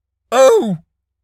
pgs/Assets/Audio/Animal_Impersonations/seal_walrus_hurt_05.wav at master
seal_walrus_hurt_05.wav